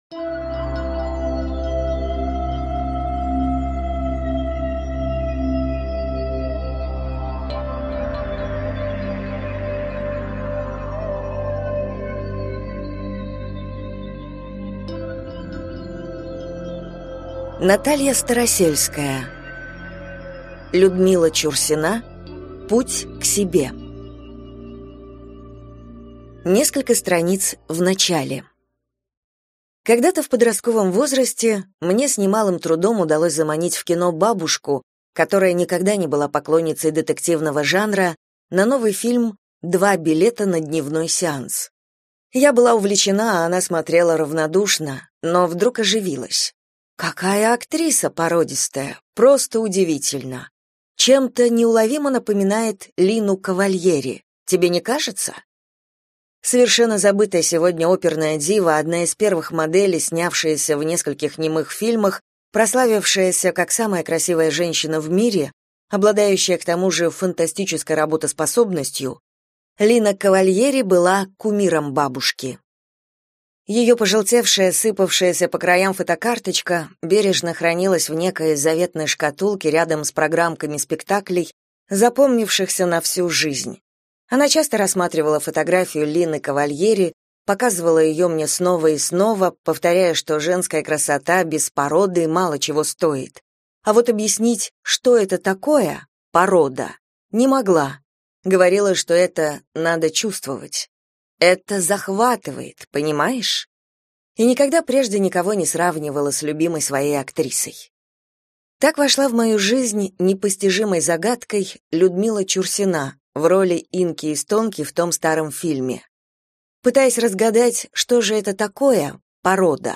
Аудиокнига Людмила Чурсина. Путь к себе | Библиотека аудиокниг